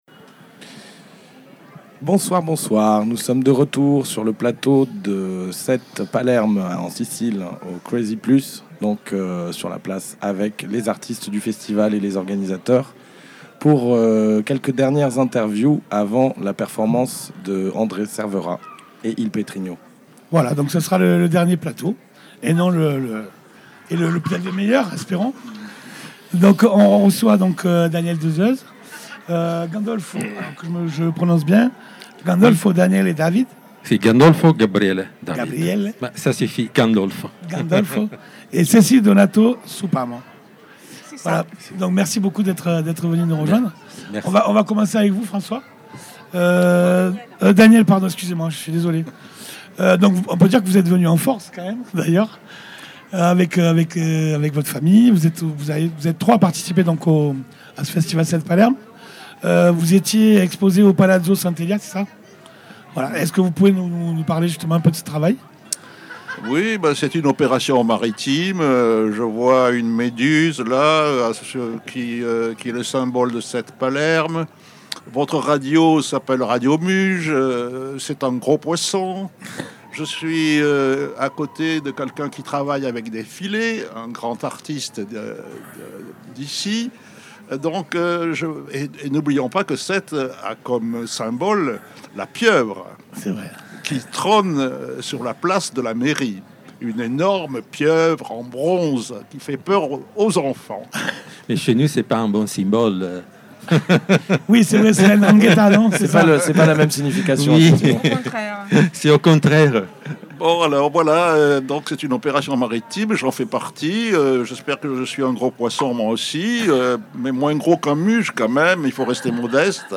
4e Episode (Extrait) du direct diffusé & enregistré le Vendredi 21 Octobre @ Cantieri Culturali alla Zisa.
Attention! Radio Muge ne peut malheureusement pas diffuser l'intégralité du direct, en raison d'un problème technique survenu lors de son enregistrement.
Nous nous excusons auprès des artistes et intervenants dont les propos sont coupés ou inexistants.